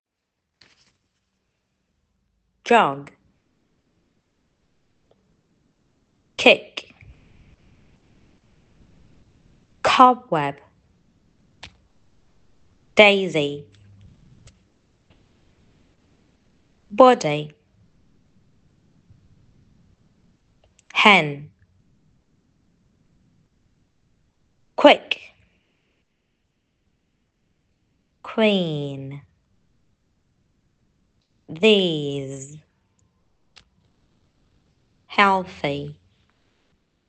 Además, aquí os dejamos un audio con otras palabras. Podéis escucharlo y escribirlas, es decir, hacer una actividad a modo de dictado.